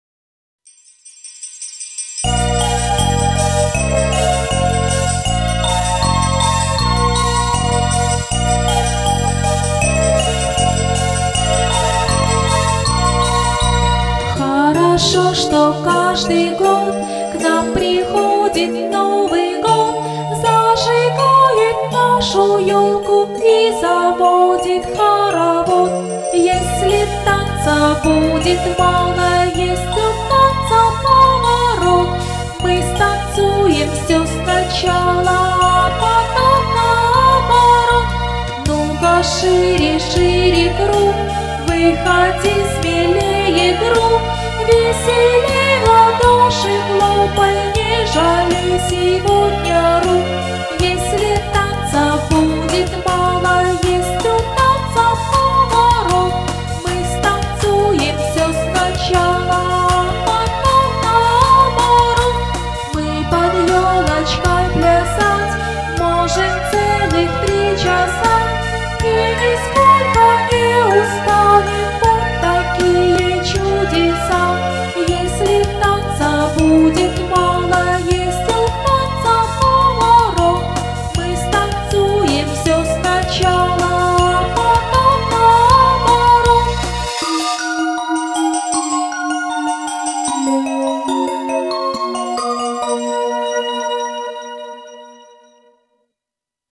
фонограмму (плюс) детской новогодней песни